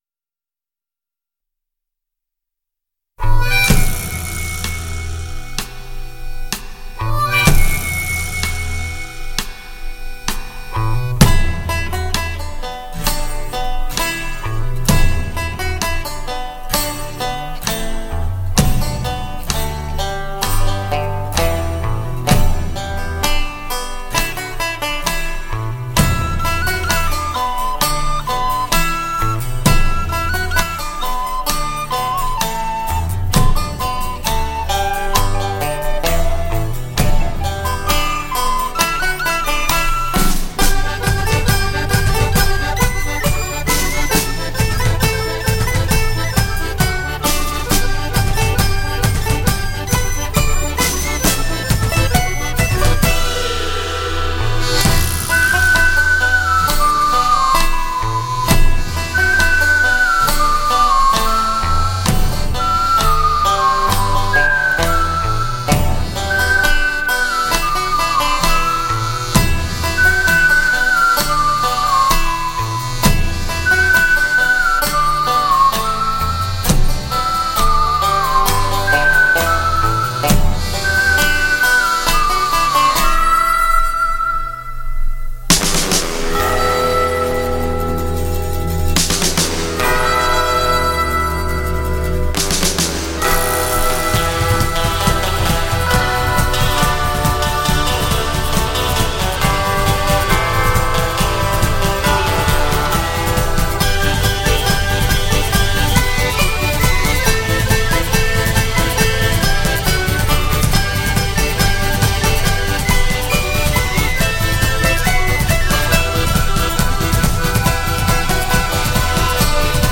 本期音乐为朋克（Punk）专题，曲风主要为斯卡朋克（Ska-Punk）风格。